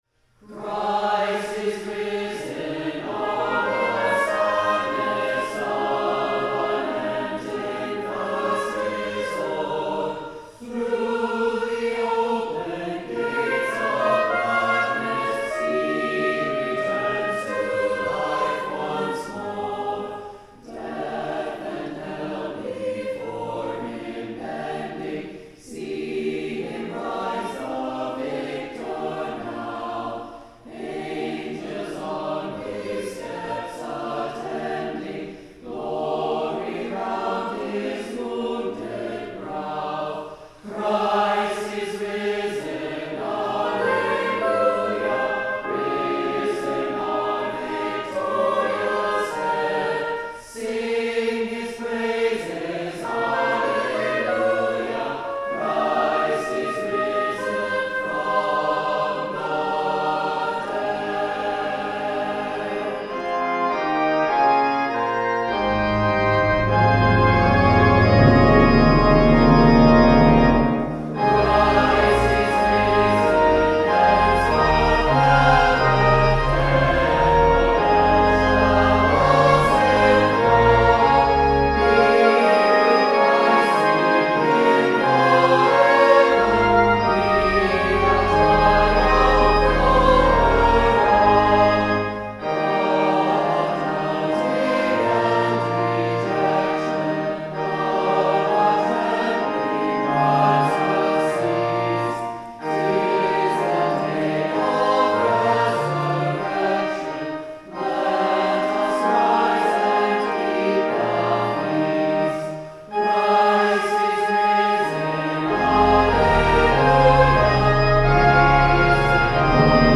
trumpet
timpani
*As noted in the introduction, this recording is adapted and slightly reordered.
The Lord’s Prayer (sung)